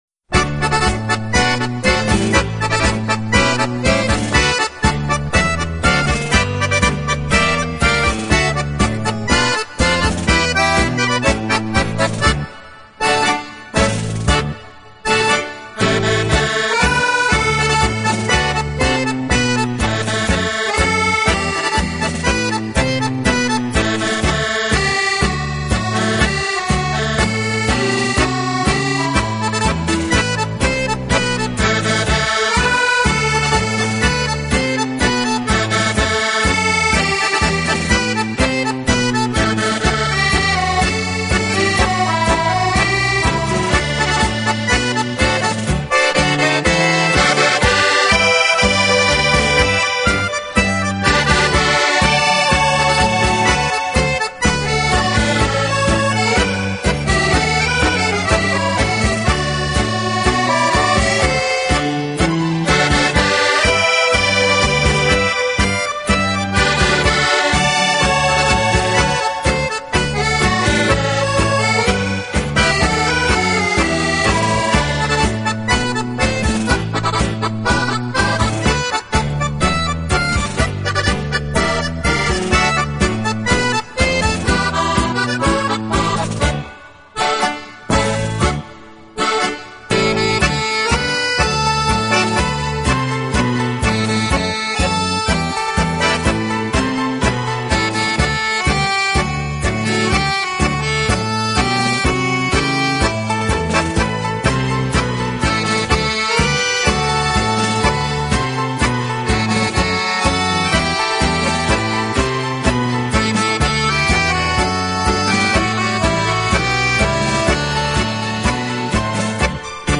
【手风琴专辑】